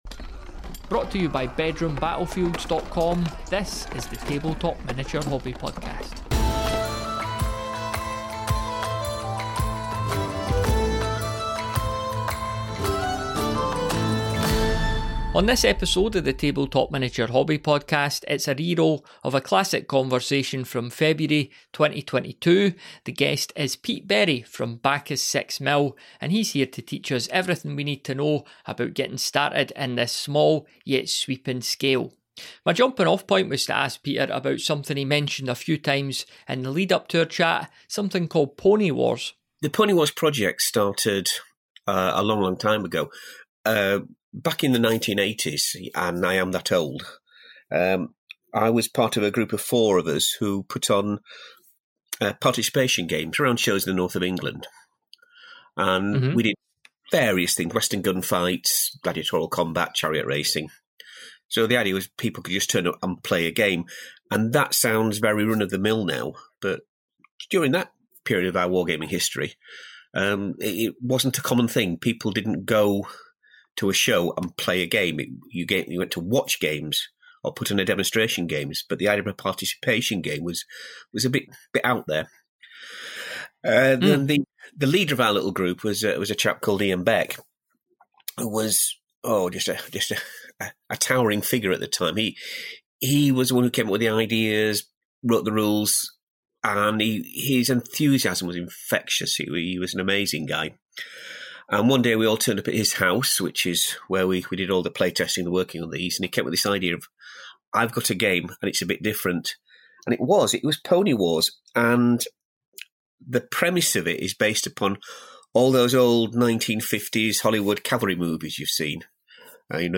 On this episode of the Tabletop Miniature Hobby Podcast, we're exploring the world of wargaming in the 6mm scale. This is a great conversation highlighting the many joys of six.